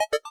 Cancel2.wav